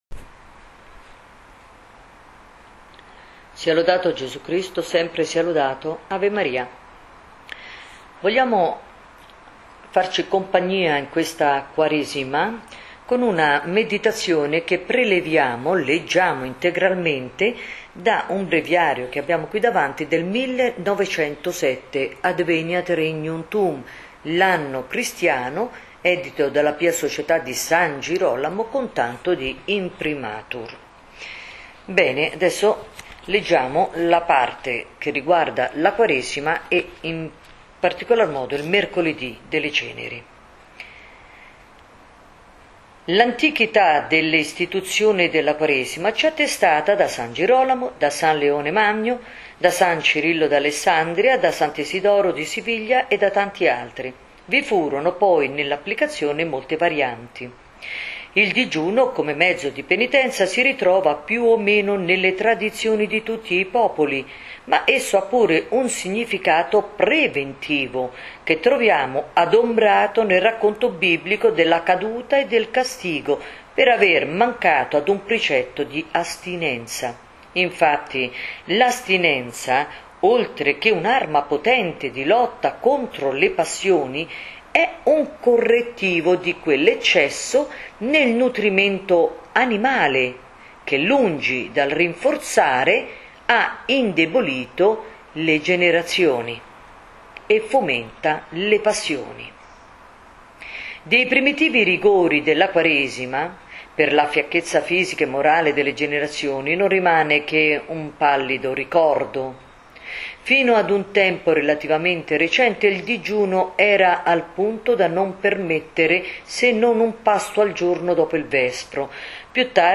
Leggeremo, fedelmente, la parte che riguarda prettamente la Quaresima, in modo particolare il Mercoledì delle Ceneri.